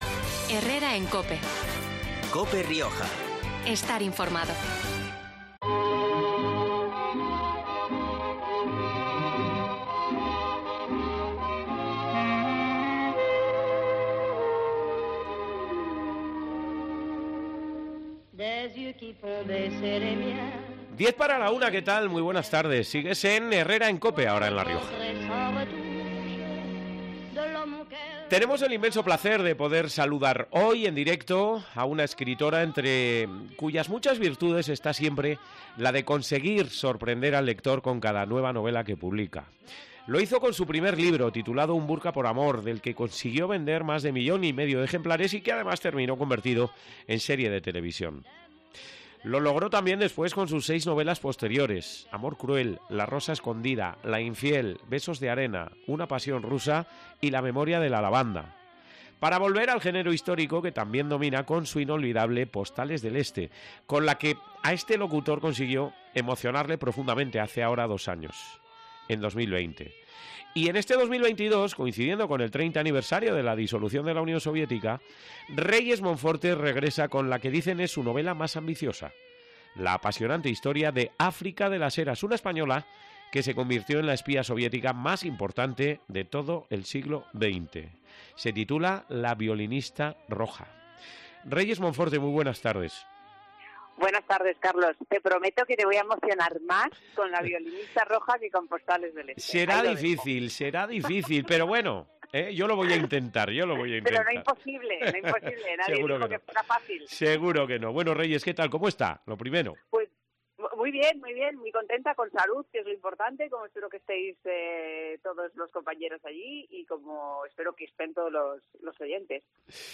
Este lunes, 23 de mayo, hemos hablado en COPE Rioja con una escritora entre cuyas muchas virtudes está siempre la de conseguir sorprender al lector con cada nueva novela que publica.